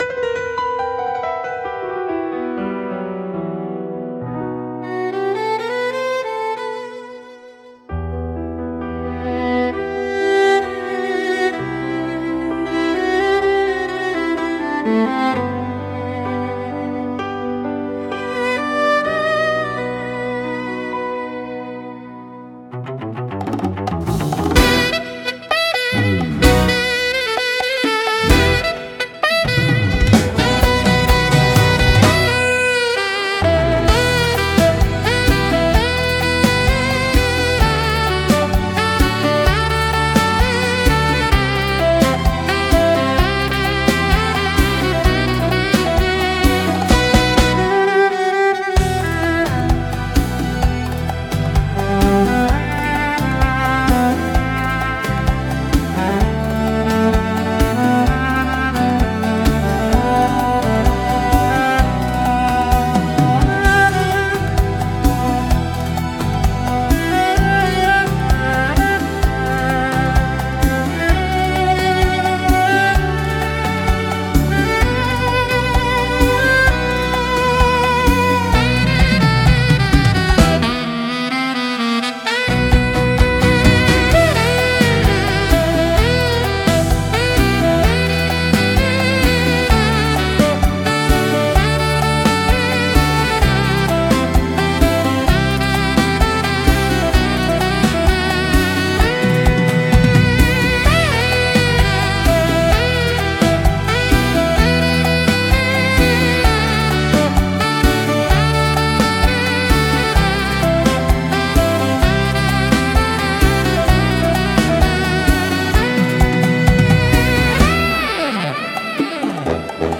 instrumental 10